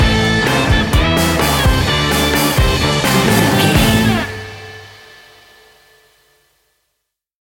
Ionian/Major
hard rock
heavy metal
distortion